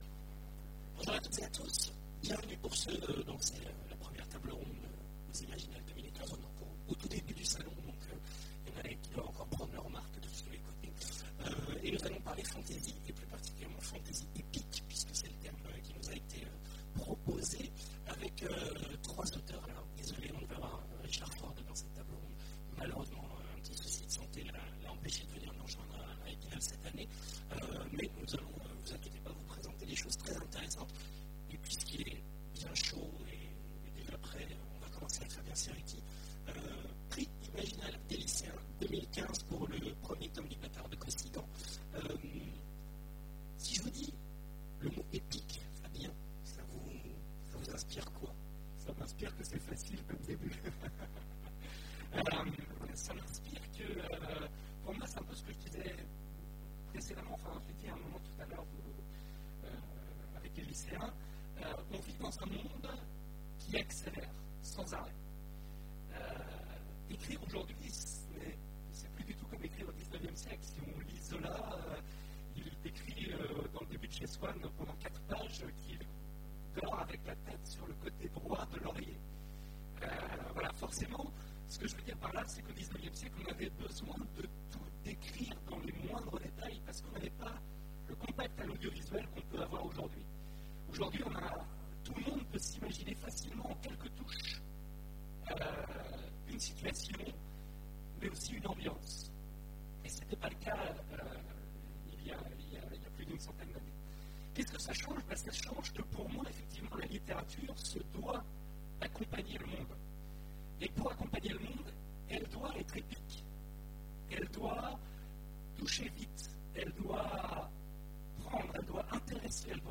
Imaginales 2015 : Conférence Vive la fantasy...
- le 31/10/2017 Partager Commenter Imaginales 2015 : Conférence Vive la fantasy...